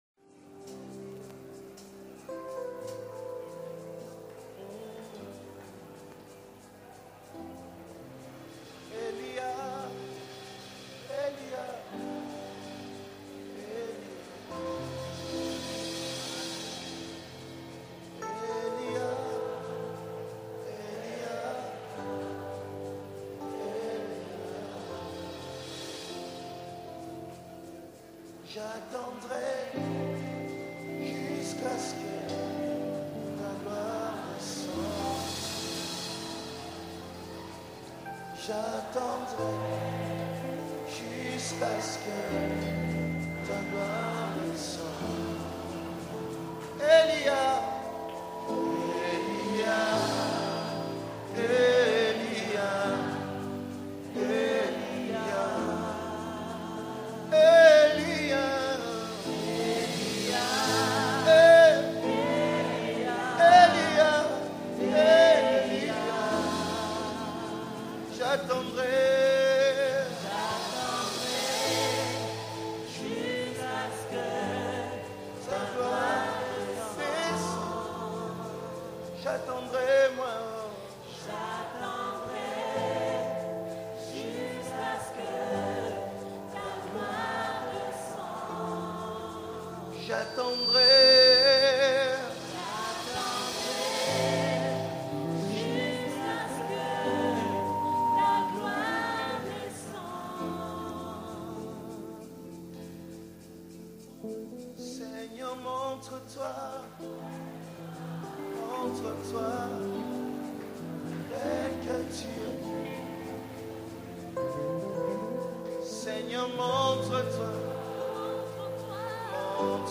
PRAYER ANTHEM